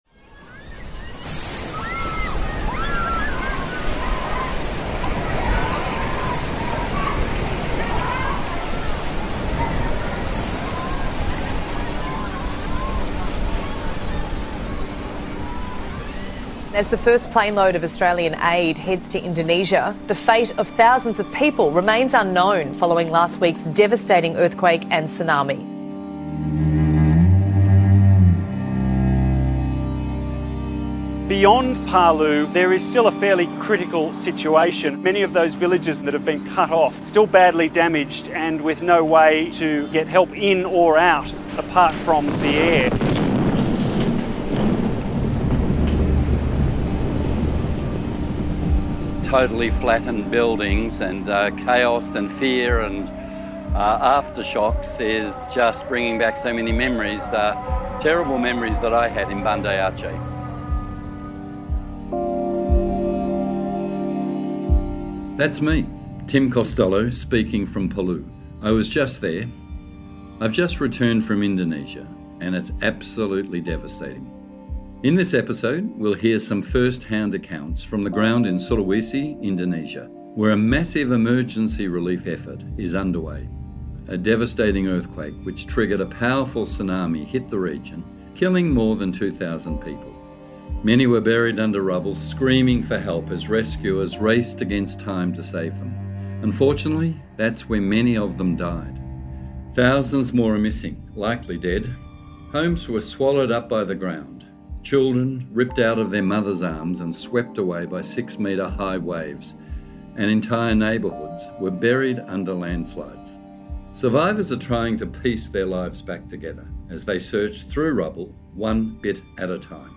In this episode we’ll hear some first hand accounts from on the ground in Sulawesi, Indonesia, where a massive emergency relief effort is underway. A devastating earthquake which triggered a powerful six-metre-high tsunami hit the region, killing more than 2,000 people.